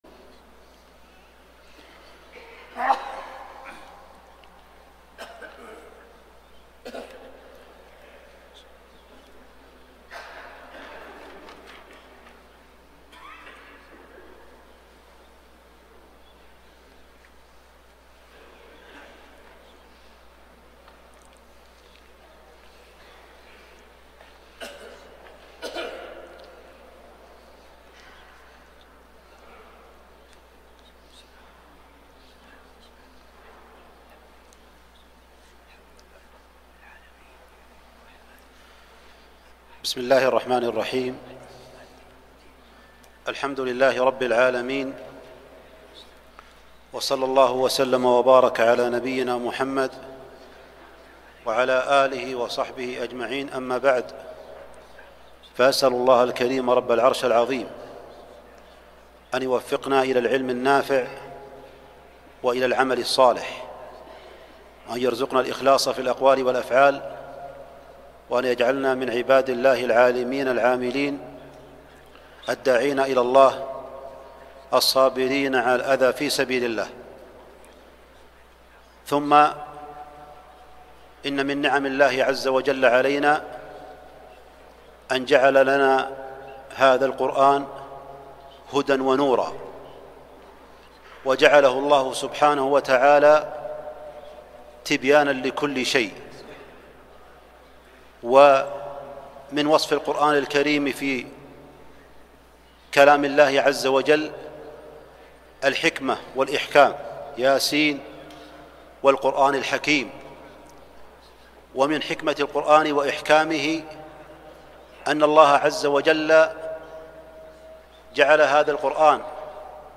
كلمة - وقفات مع سورة العصـــر في جامع الراجحي بحي الجزيرة 9 جمادى ثاني 1441